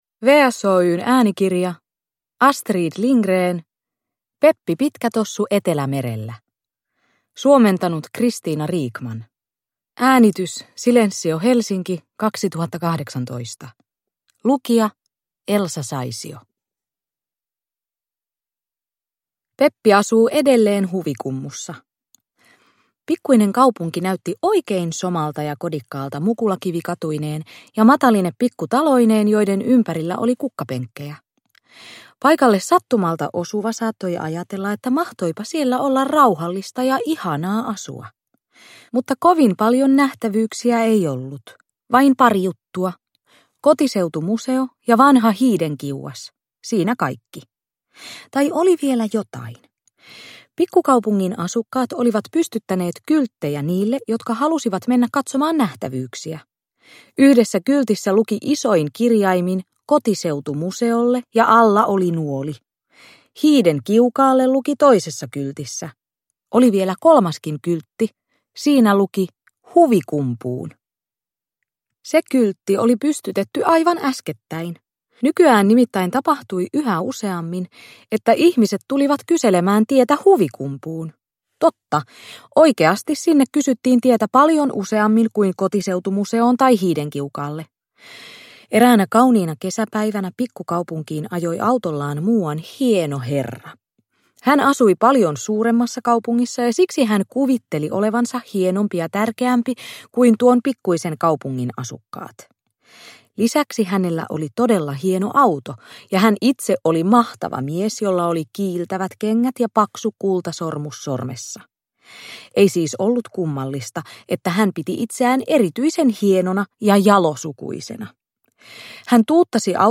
Uppläsare: Elsa Saisio